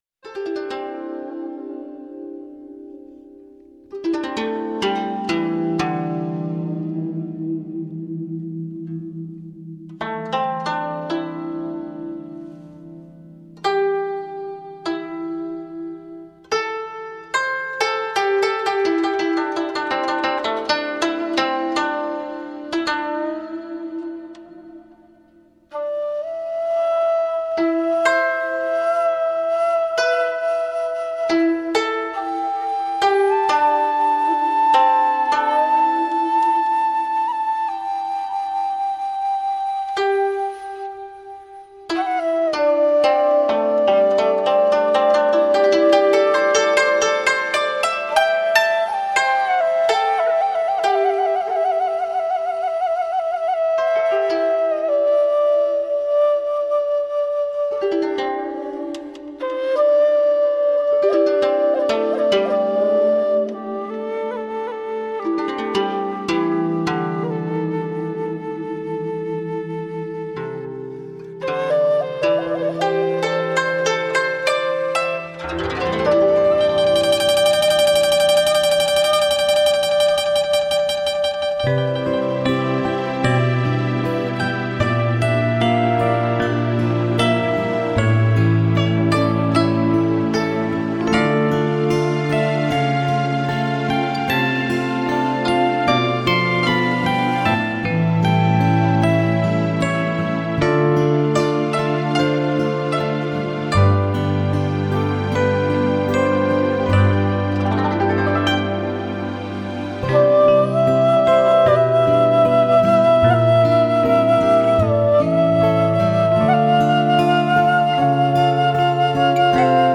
浪漫新世纪音乐
听那箫音起落在竹林间的心跳
不疾不徐，悠悠荡荡
为古灵赋予新魂，形成一种宁谧、冥想、且更深化心灵的乐风，